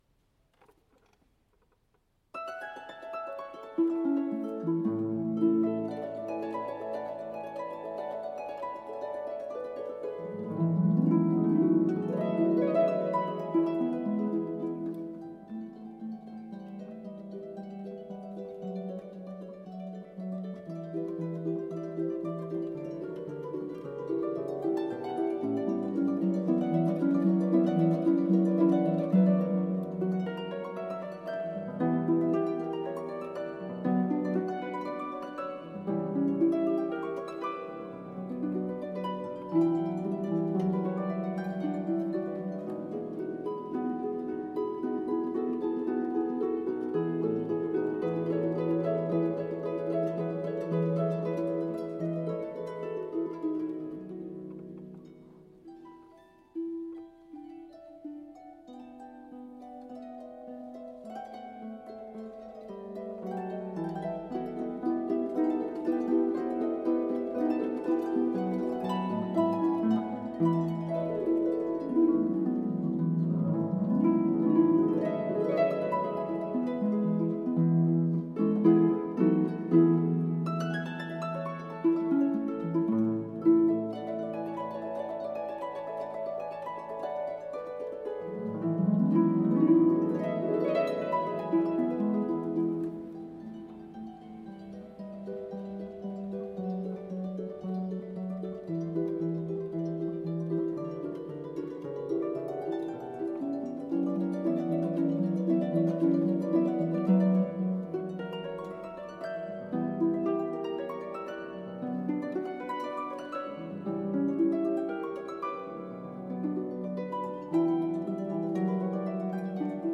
Harp duo
for two pedal harps